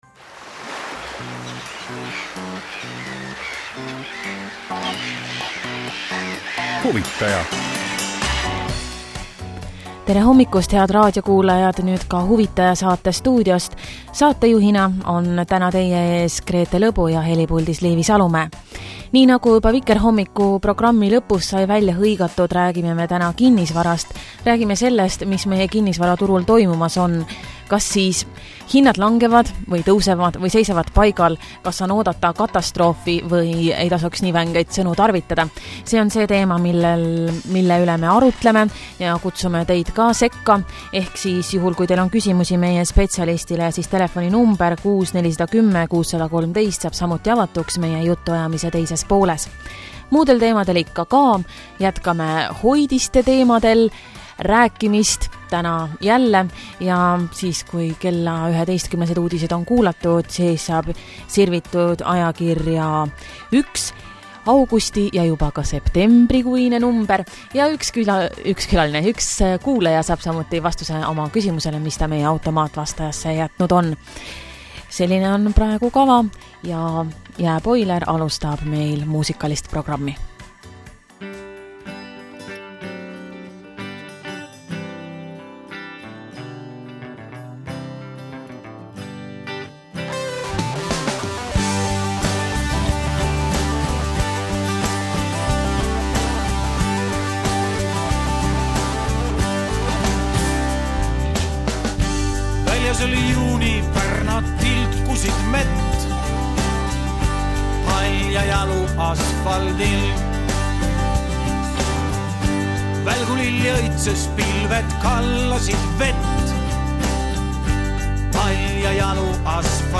Raadiointervjuu kinnisvaraturu teemadel Vikerraadio saates “Huvitaja”